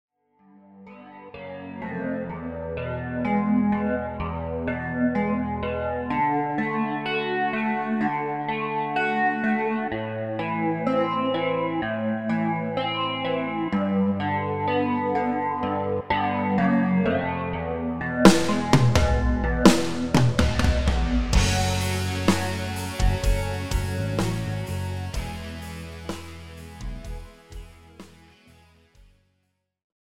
Žánr: Rock
BPM: 62
Key: E
MP3 s melo. linkou